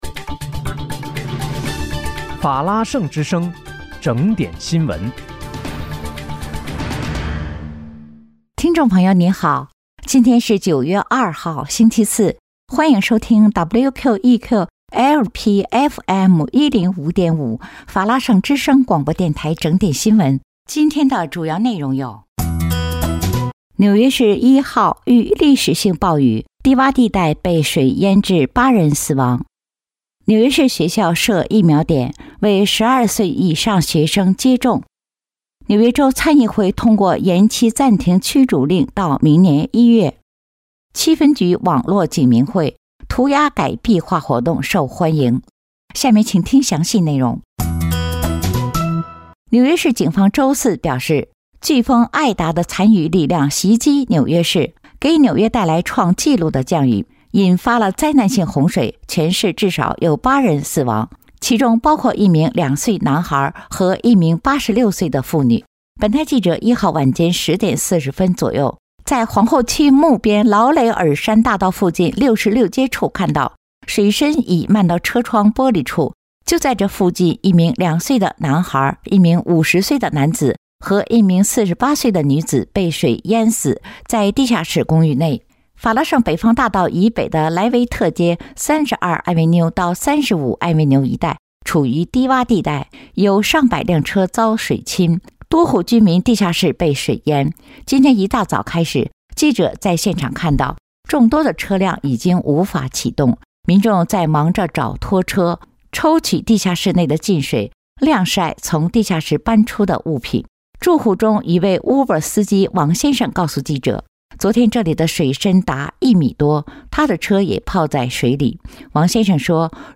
9月2日（星期四）纽约整点新闻